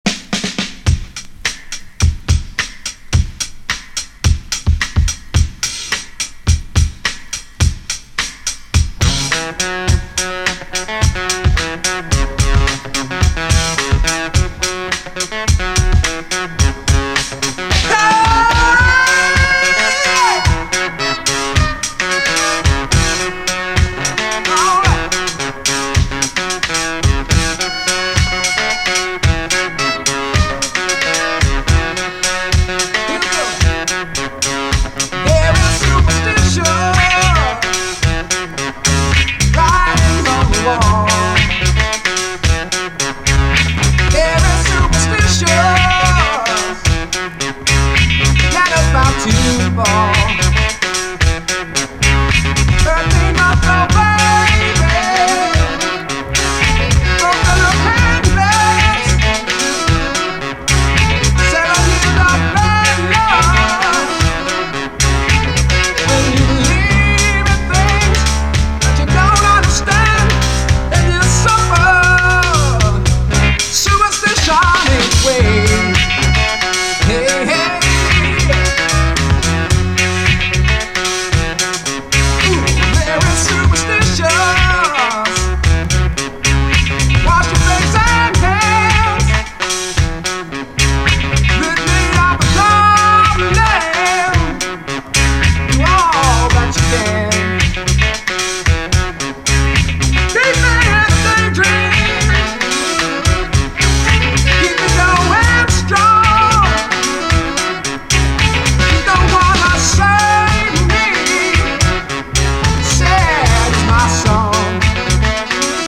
SOUL, 70's～ SOUL, REGGAE
アーリー70’SのUK産企画モノ・レゲエ・カヴァー集！